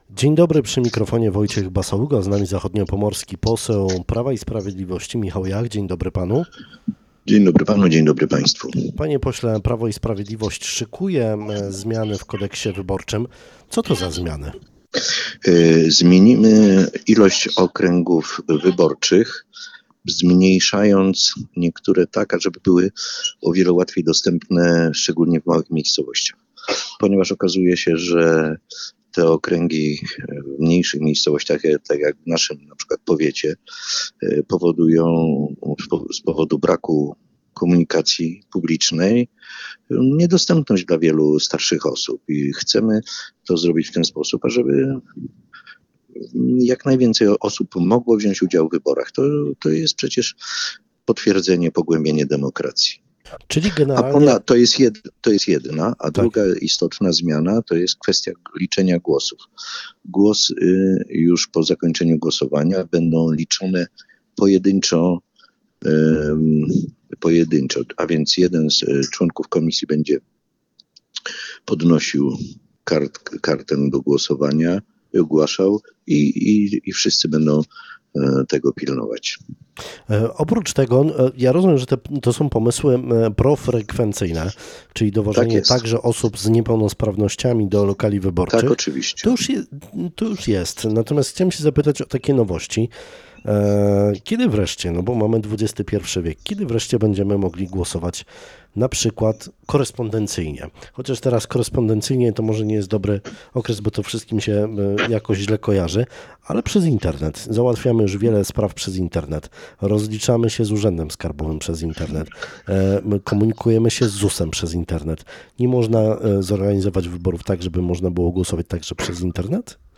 PiS chce zmienić kodeks wyborczy, aby jak mówi – przekonać więcej Polaków do głosowania, a także wykluczyć możliwość nadużyć w komisjach do głosowania. W poniedziałek mówił o tym w Twoim Radiu poseł Michał Jach.